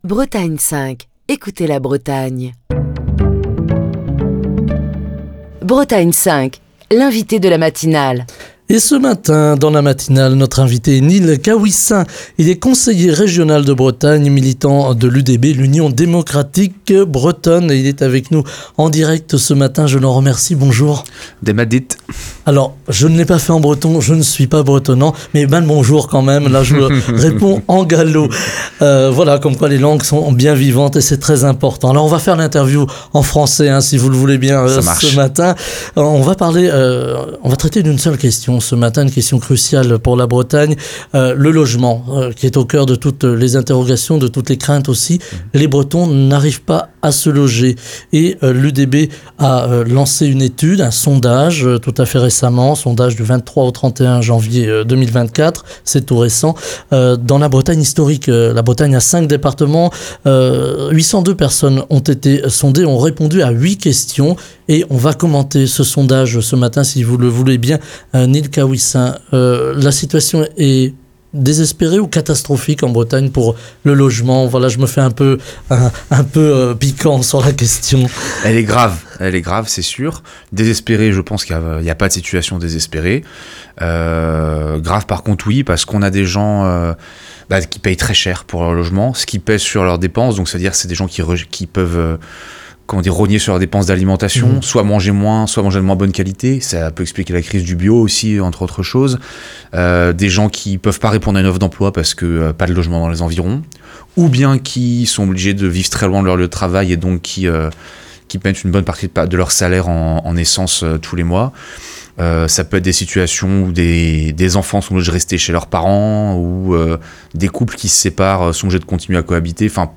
Nil Caouissin, conseiller régional de Bretagne, militant de l'UDB | Bretagne5
Ce lundi dans Bretagne 5 Matin, nous revenons sur le sondage sur le logement en Bretagne commandé par l'Union démocratique bretonne à l’Ifop avec notre invité, Nil Caouissin, conseiller régional de Bretagne, militant de l'UDB (L'Union Démocratique Bretonne), qui commente les résultats de cette étude.